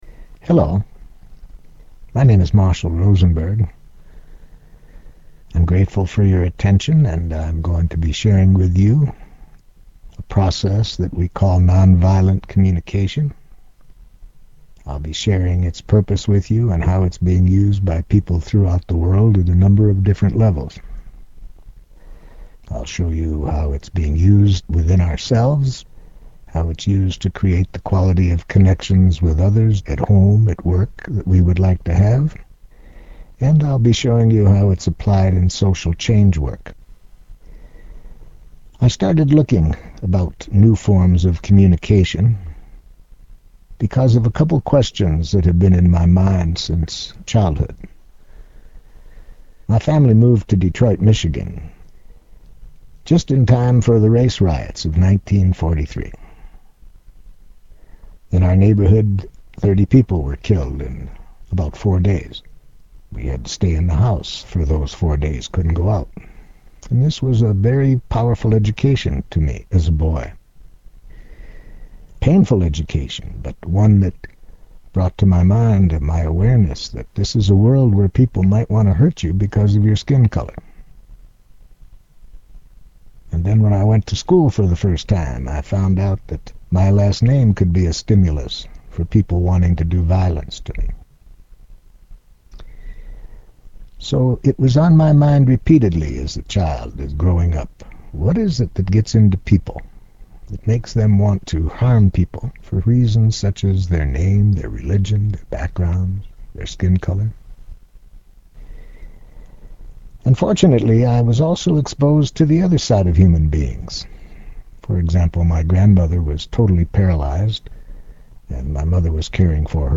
Tags: Public Speaking Public Speaking Audio clips Public Speaking Audio Self Development Self Development clips